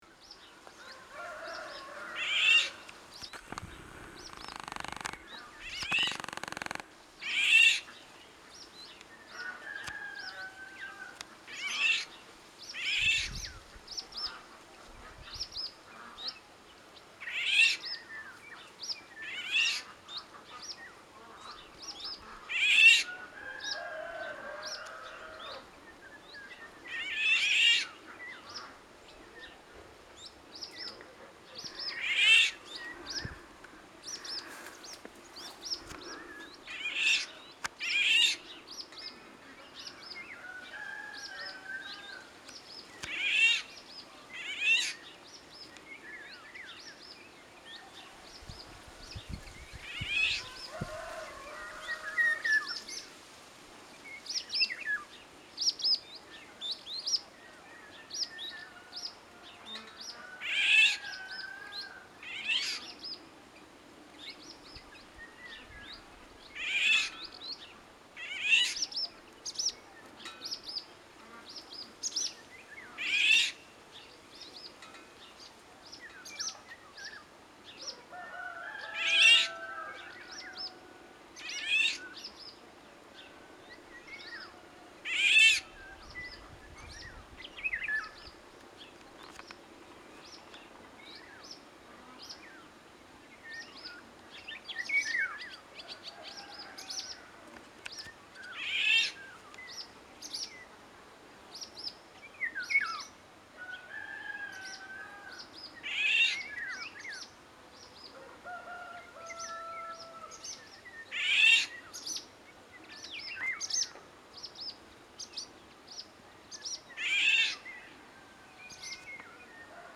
The Eurasian Golden Oriole is a very nice bird and its song includes fluty, melodious notes.
CALLS AND SONGS:
The Eurasian Golden Oriole’s alarm call is a hoarse, mewing, Jay-like “kra-eik” also given in aggressive behaviour.
The song is a mellow, flute-like whistle, a yodelling “tjoh-wlee-klee-ooh”. The last note is hollow and descending. We can also hear several harsh calls.
Duets between mates can be heard too, with the female uttering a short song “skweeeeer” while answering to the whistling male’s song.